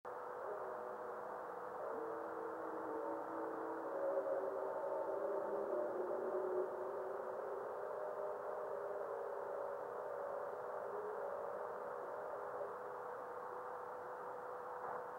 Observer's Comments:    Small fireball., short radio reflection.
61.250 MHz right channel and 83.250 MHz left channel
Radio spectrograph:  Fireball at end of 11:41 UT minute:  61.250 MHz forward scatter reflection above white line, 83.250 MHz below white line.